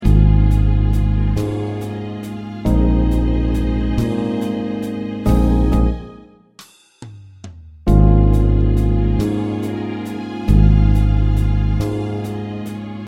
Accompaniment track preview